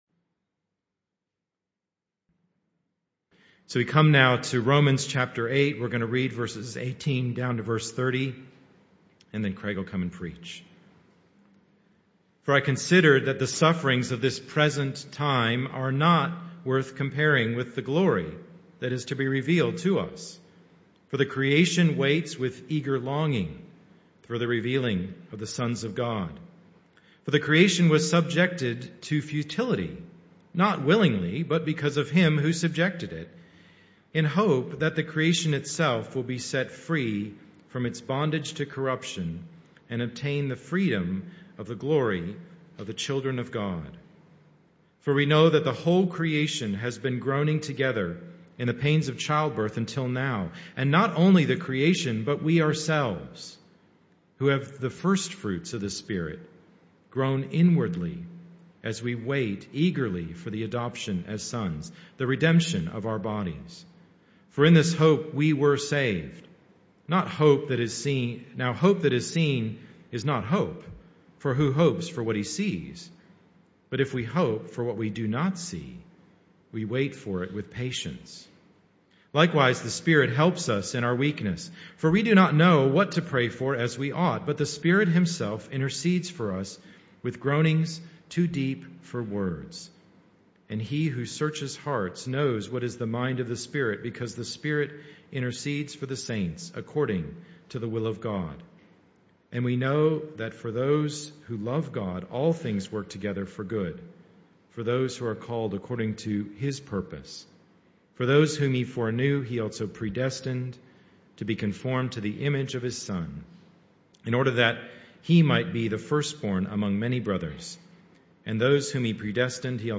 This talk was a one-off that took place in the AM Service.
Service Type: Morning Service